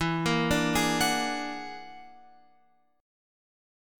E 9th Flat 5th